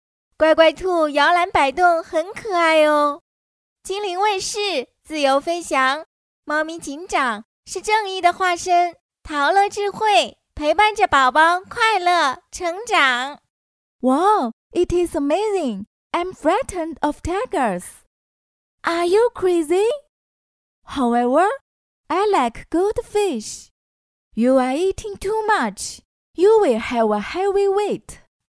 女声配音